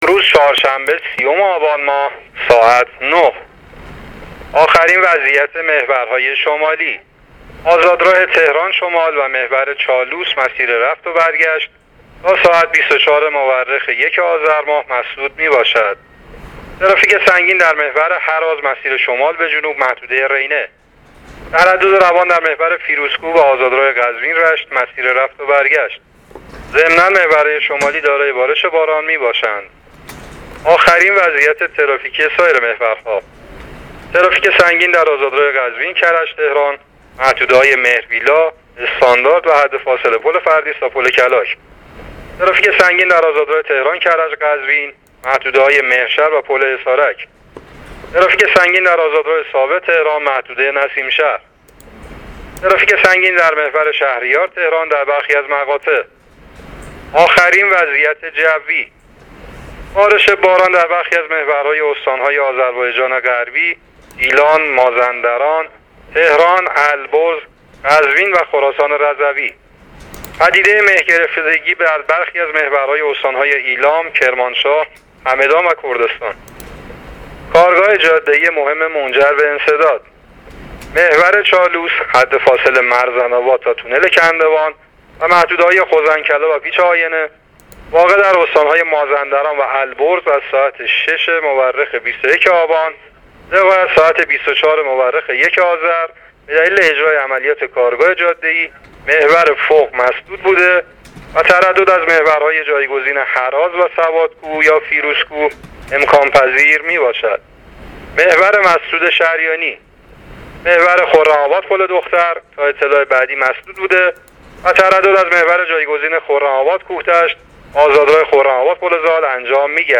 گزارش رادیو اینترنتی از آخرین وضعیت ترافیکی جاده‌ها تا ساعت ۹ روز ۳۰ آبان؛